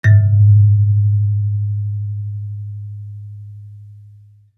kalimba_bass-G#1-pp.wav